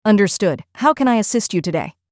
78883-voiceover.mp3